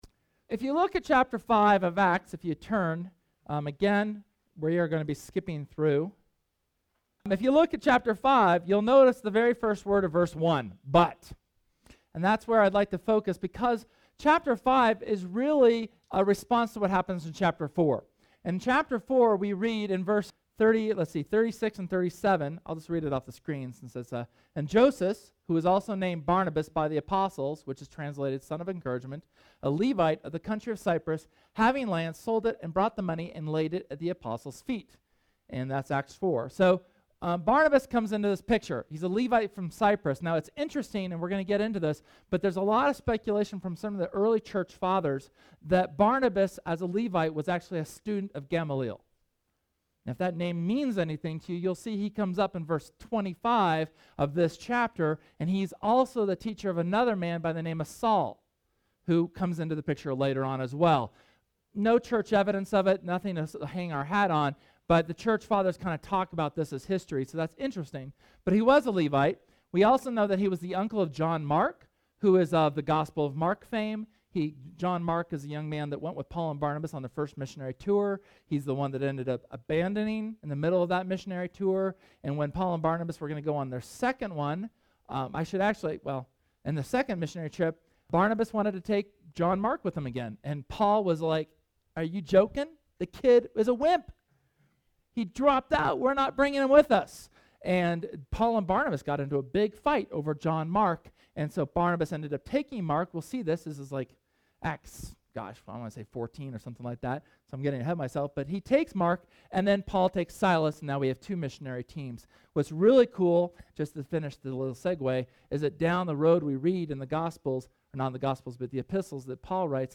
SERMON: Fraud, Fear and Faith – Church of the Resurrection